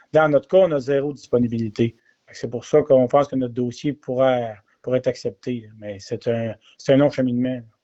C’est ce qu’a expliqué le maire, Alain Vouligny, en entrevue alors que la MRC de Nicolet-Yamaska a déjà donné son accord pour continuer ce projet.